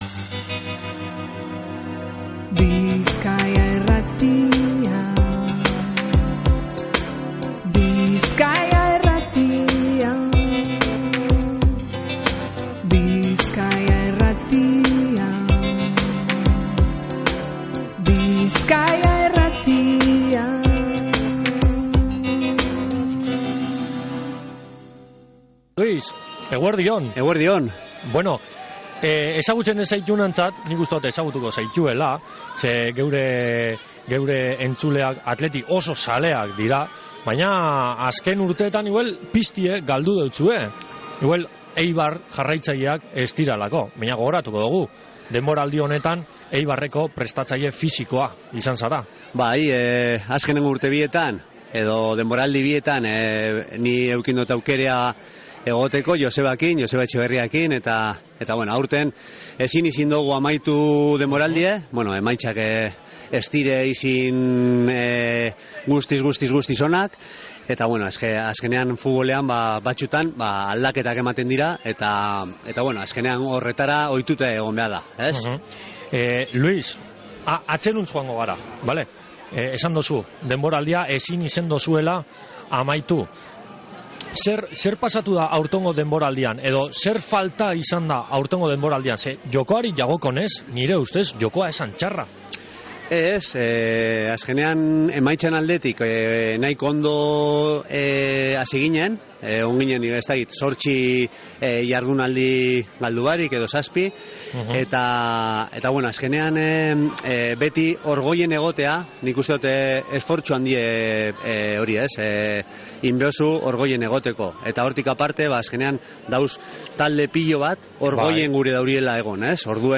Luis Prieto, SD Eibarreko prestatzaile fisiko eta futbol jokalari ohiari alkarrizketea
LUIS-PRIETO-ELKARRIZKETEA.mp3